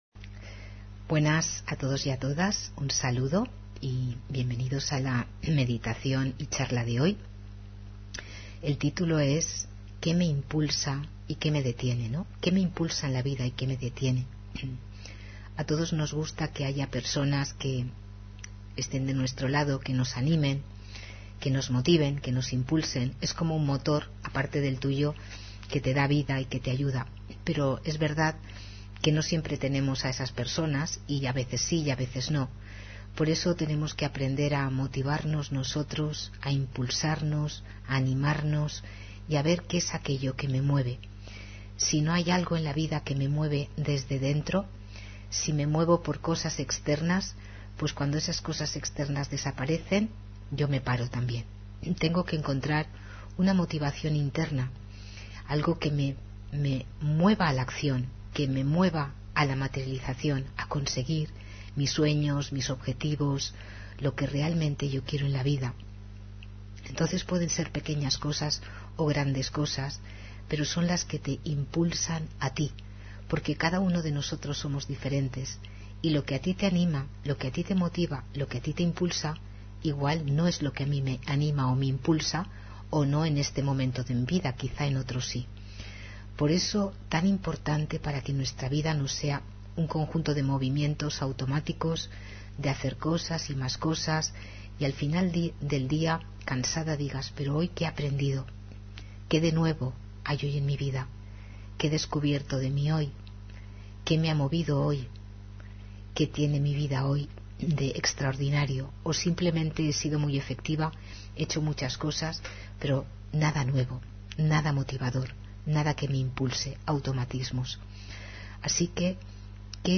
Meditación y conferencia: ¿Qué te impulsa? ¿Qué te detiene?